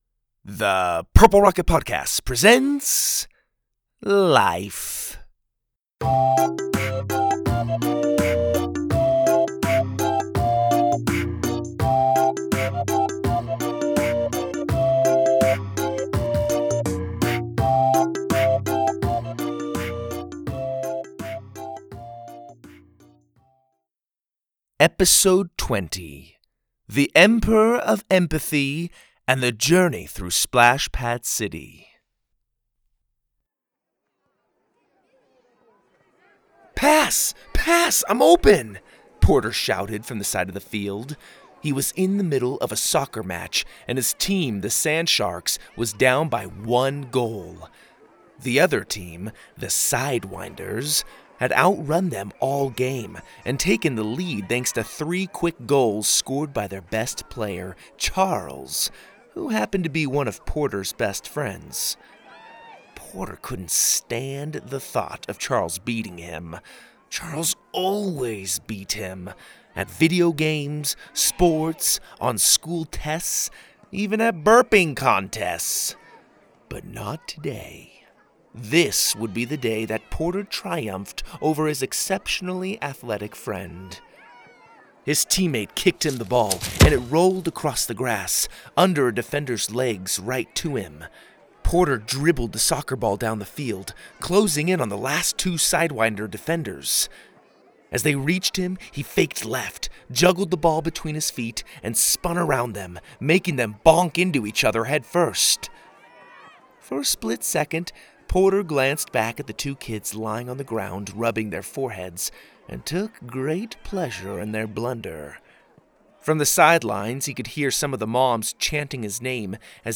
Stories For Kids, Kids & Family